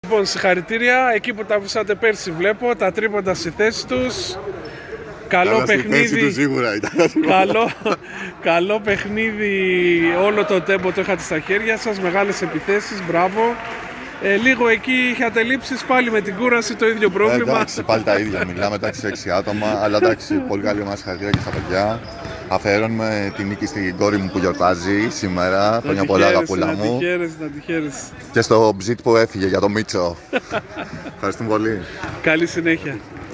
GAMES INTERVIEWS: